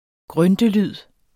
Udtale [ ˈgʁœndə- ]